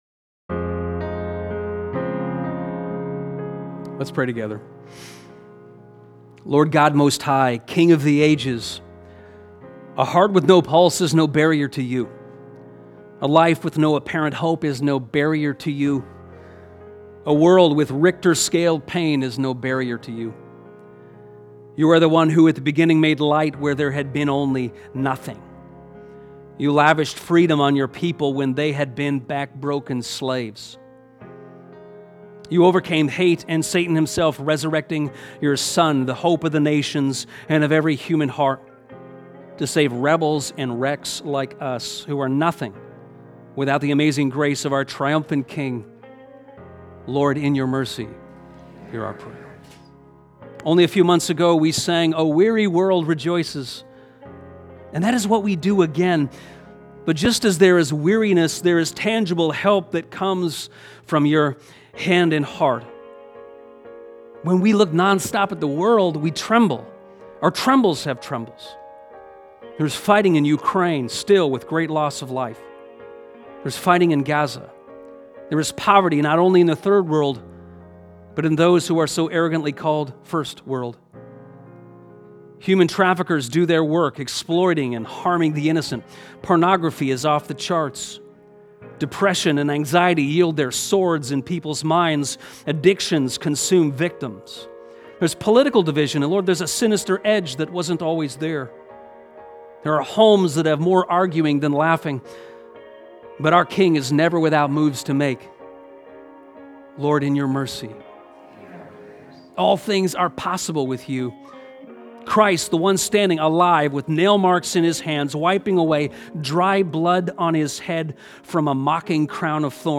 Join in with this prayer from Easter Sunday.